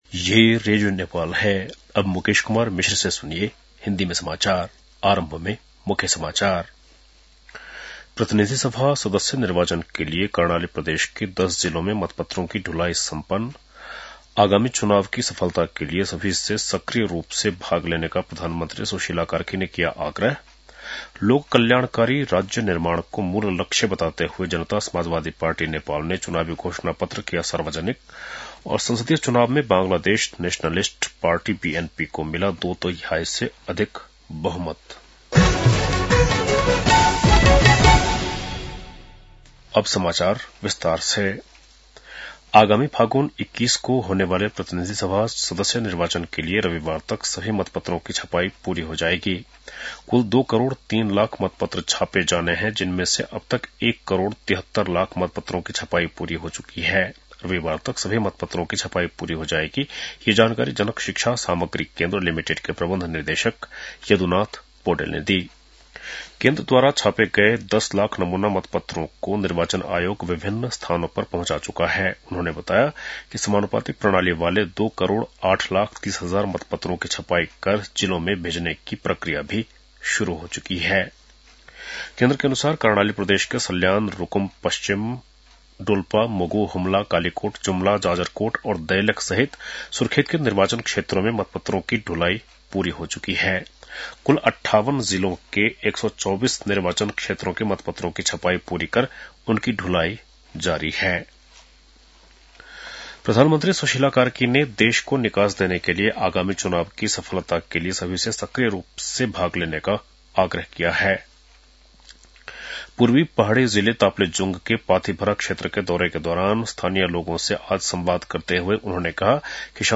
बेलुकी १० बजेको हिन्दी समाचार : १ फागुन , २०८२
10-pm-hindi-news-1-3.mp3